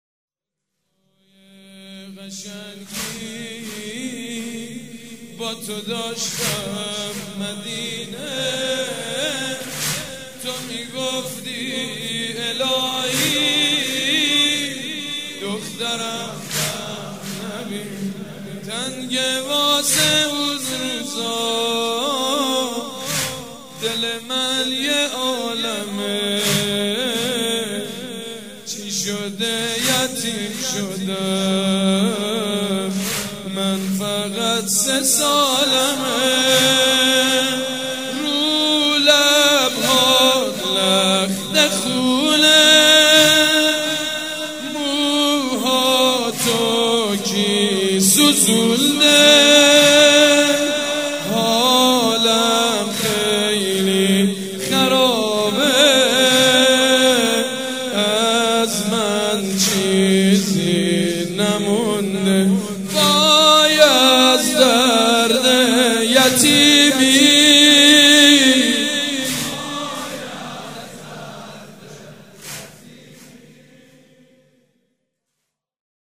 شب سوم محرم 95/هیئت ریحانه الحسین(ع)
حاج سیدمجید بنی فاطمه/روضه